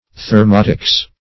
Thermotics \Ther*mot"ics\, n. The science of heat.